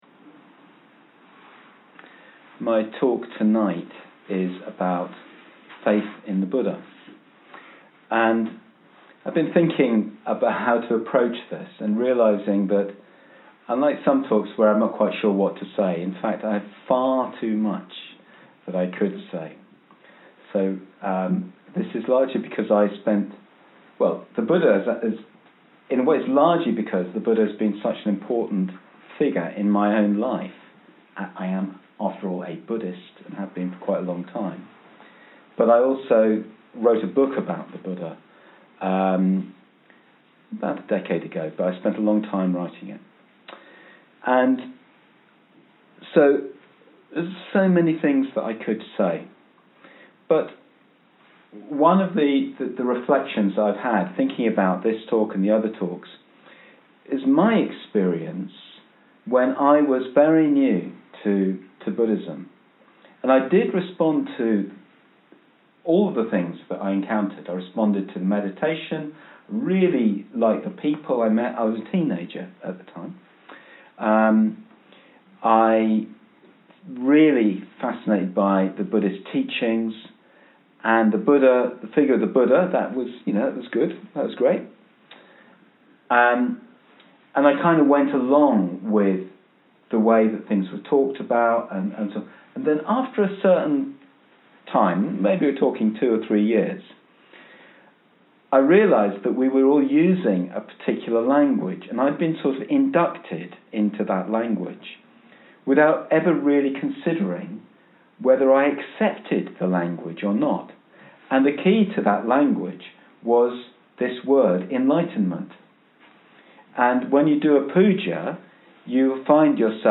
Cardiff Buddhist Centre, 23:04:17 (30 mins)